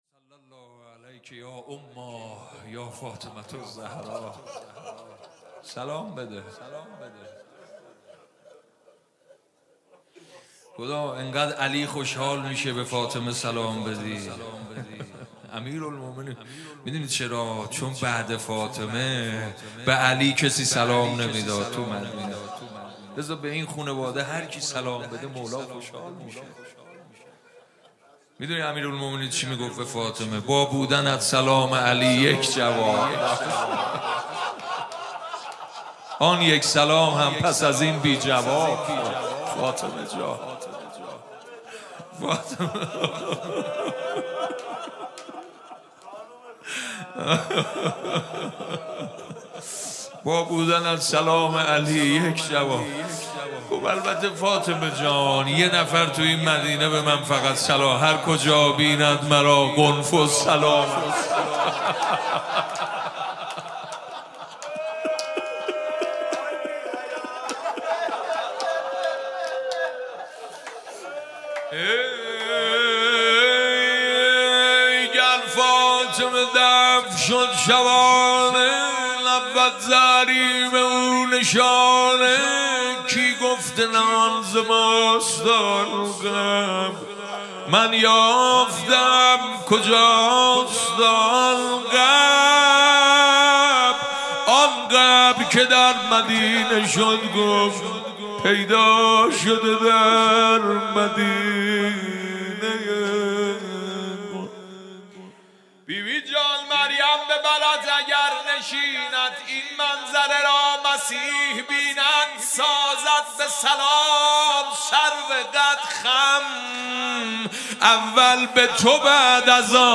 سخنرانی: ضرورت مواجهه با عالم دینی و انسان مهذب برا...